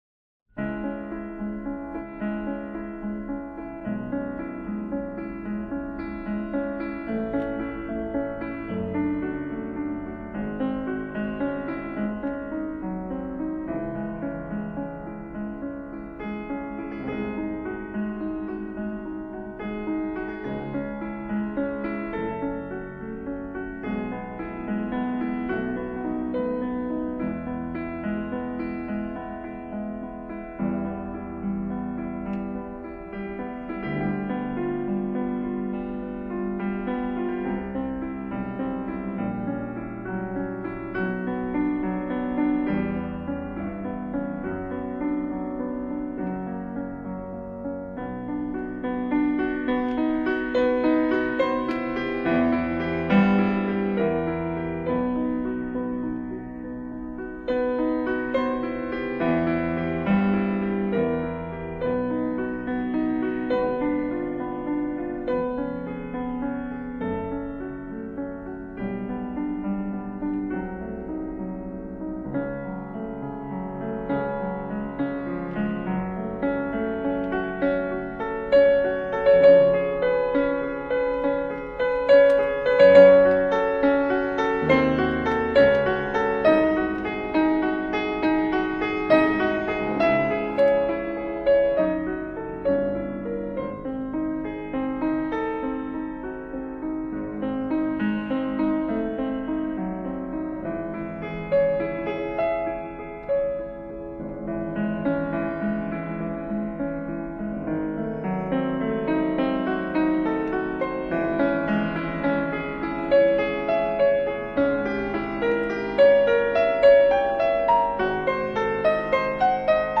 Музыка для релаксации
соль минор»